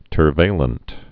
(tər-vālənt, tûrvā-)